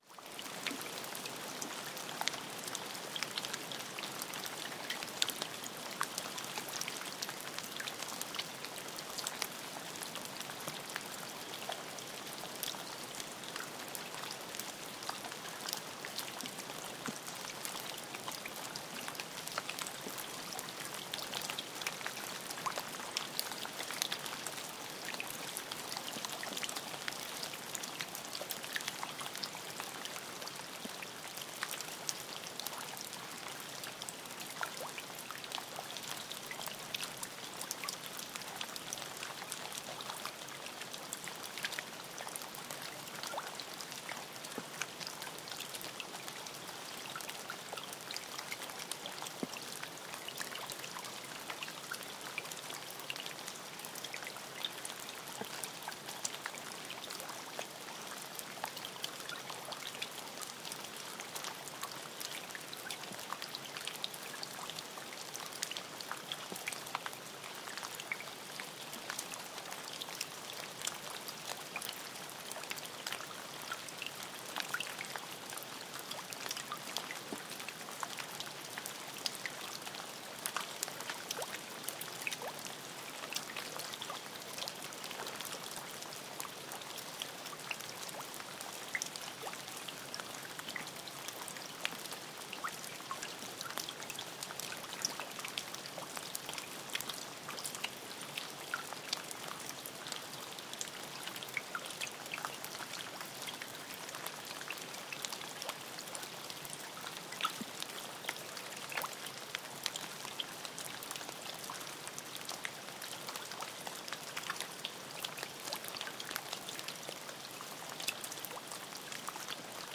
В подборке представлены природные звуки, медитативные мелодии и фоновые шумы для релаксации, работы и сна.
Мелкий дождь